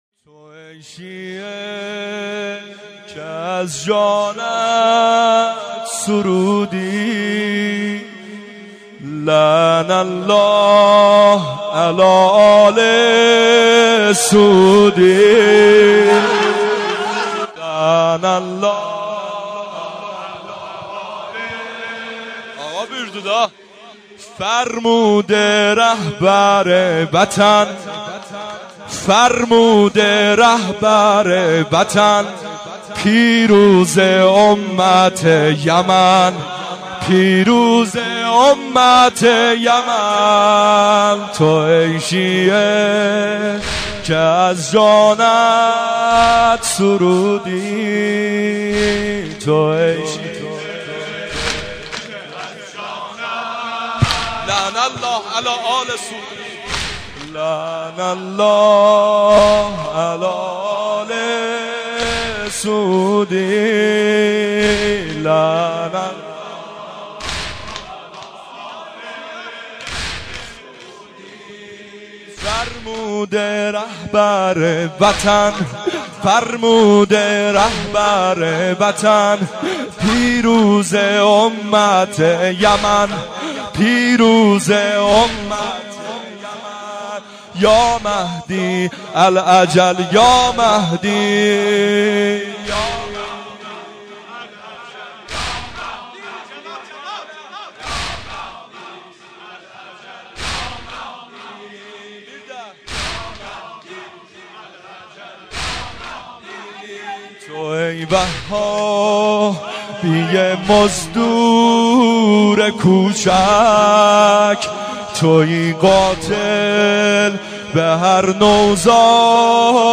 هیئت عاشورائیان زنجان
مداحی جدید